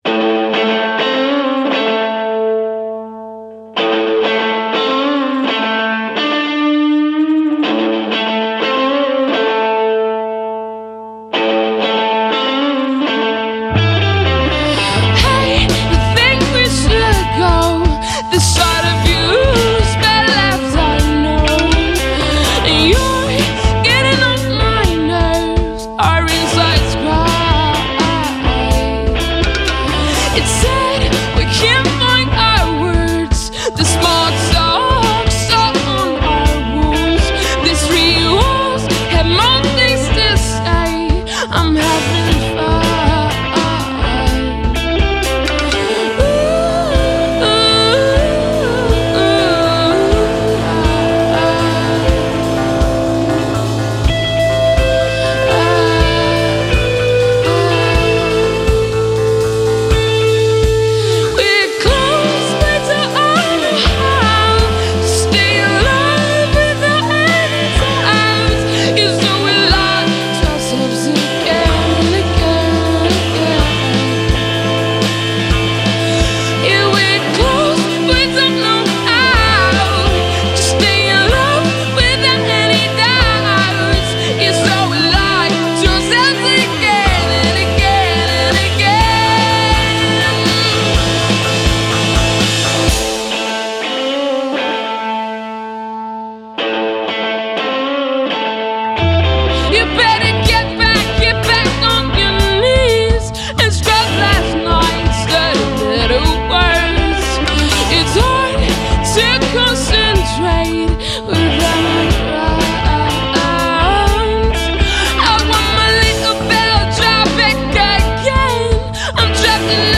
indie / psychedelic / electro